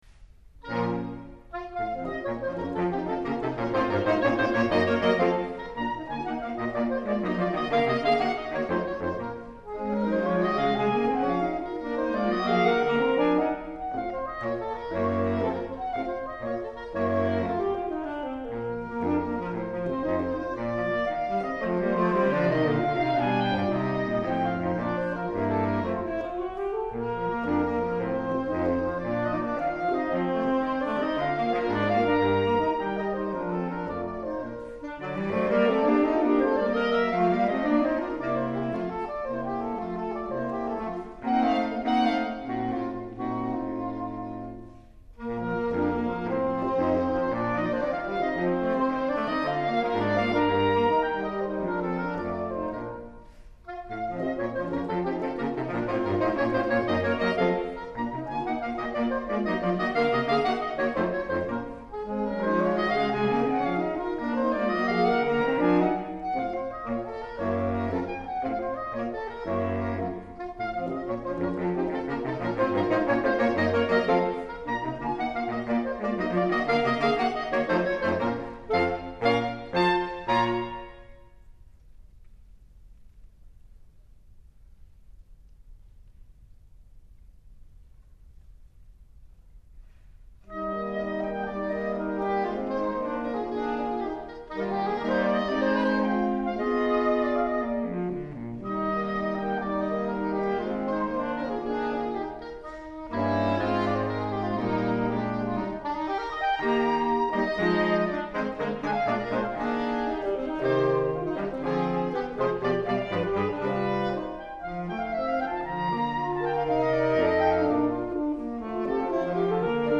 12月23日 第34回長崎県アンサンブルコンテスト　……諫早文化会館
サクソフォーン四重奏・J.B.サンジュレー／サクソフォン４重奏曲第１番　作品53より